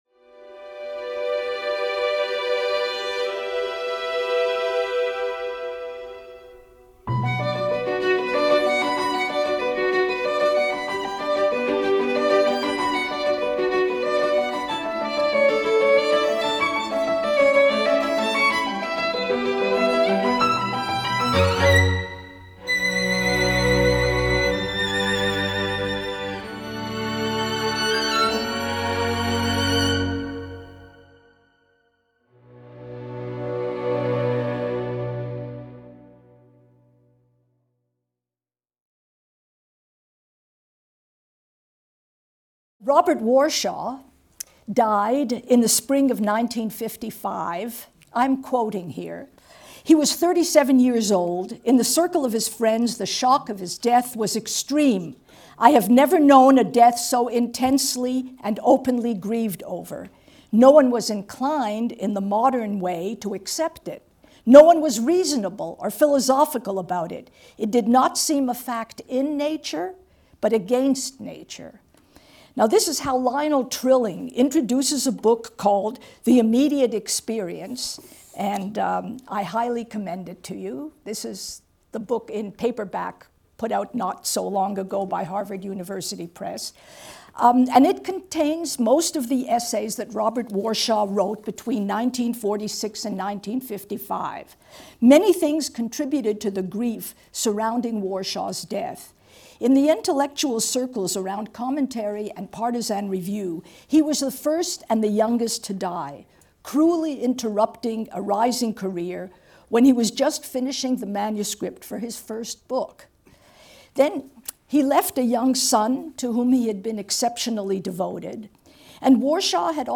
As Professor Wisse discusses in this lecture, during Warshow’s short...